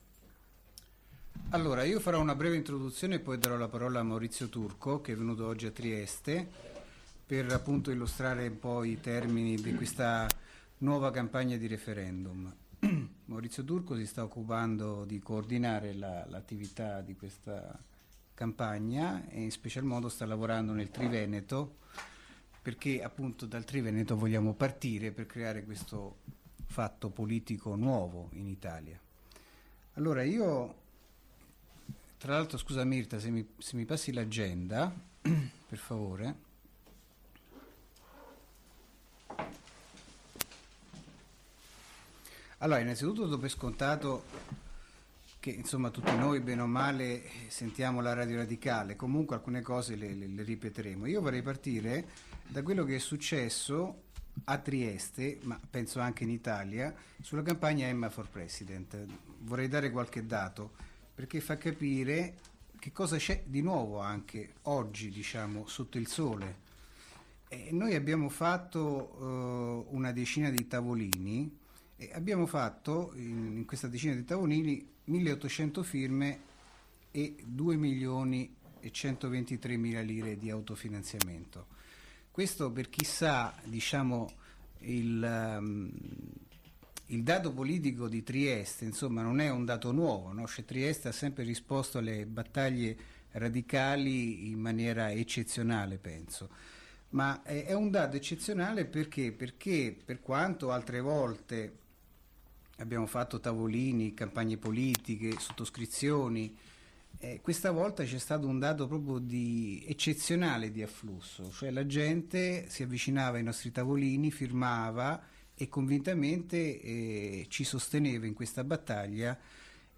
Assemblea degli iscritti e simpatizzanti per il lancio di una nuova campagna referendaria. Nel corso dell’intervento si è analizzata anche il successo della iniziativa “Emma for President” (Registrazione di Radio Radicale)